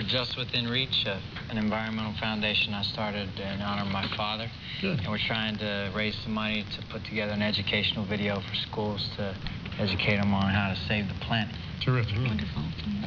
Sounds Recorded From TV Shows
I apologize for the quality of the sounds as I was not able to directly line-in record them, so they are slightly fuzzy